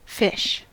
Ääntäminen
Vaihtoehtoiset kirjoitusmuodot (harvinainen) ghoti Synonyymit angle donkey mark rifle Go Fish donk rummage drop in a line pigeon sucker Ääntäminen : IPA : /ˈfɪʃ/ UK : IPA : [fɪʃ] US : IPA : [fɪʃ] NZ : IPA : /ˈfɘʃ/ Tuntematon aksentti: IPA : /fĭsh/